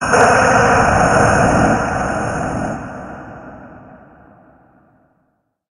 Cri de Florizarre Gigamax dans Pokémon HOME.
Cri_0003_Gigamax_HOME.ogg